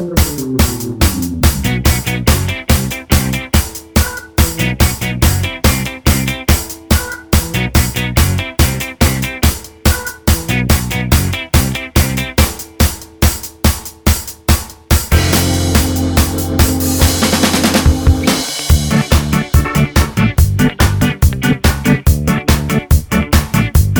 no Backing Vocals Ska 3:02 Buy £1.50